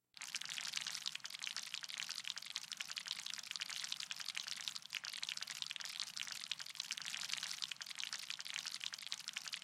На этой странице собраны звуки микроскопа — от щелчков регулировки до фонового гула при работе.
Звук перемещения микробов под микроскопом